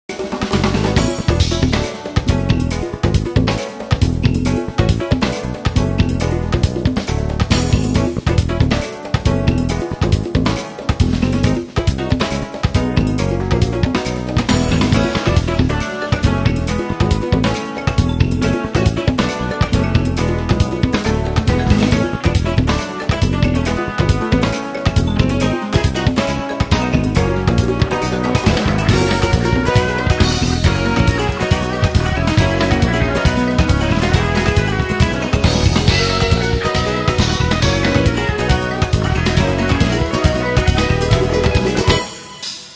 progr. Keyb. Perc. Bass/Guitar
Sax Soprano
Vocal
Guitar
Keyboards - el. piano
Percussion